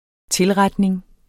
Udtale [ ˈtelˌʁadneŋ ]